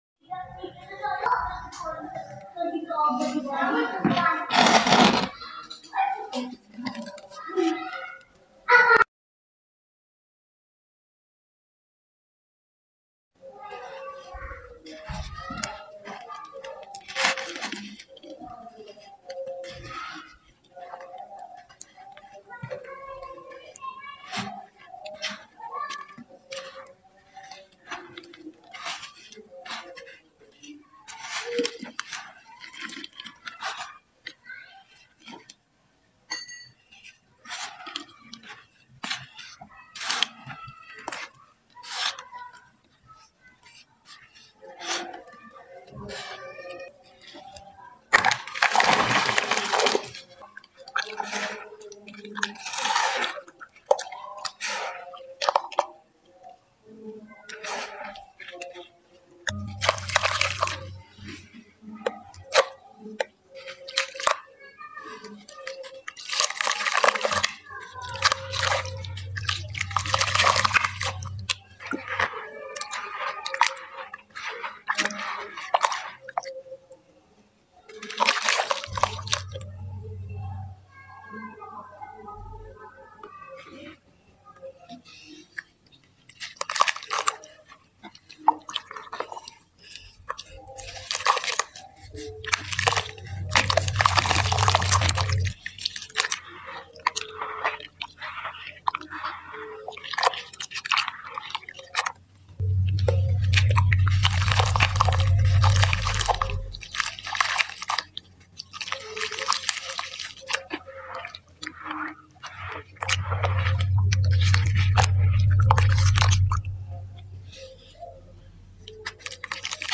Mp3 Sound Effect
brown 🟤 sand ⌛💦😋 satisfying crumbling